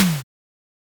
eleSnare02.mp3